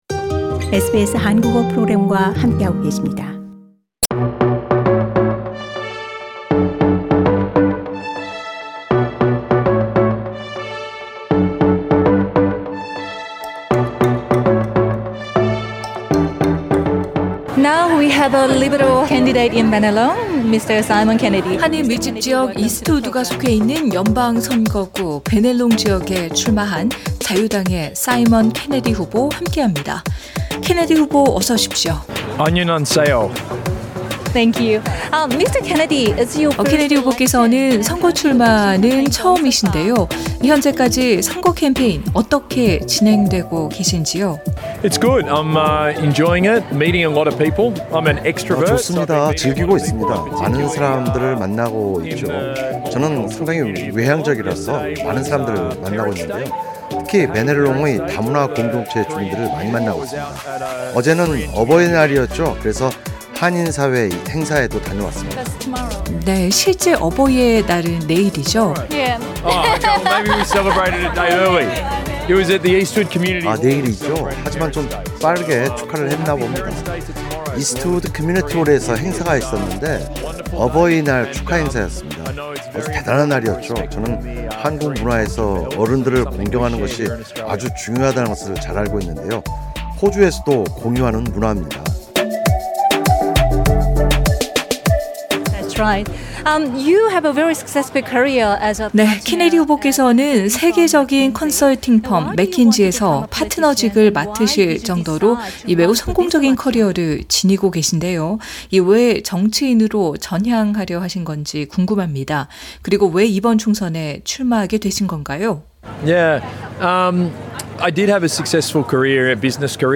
2022 연방 총선 특집 연쇄 대담: 리드 선거구 자유당 현 피오나 마틴 의원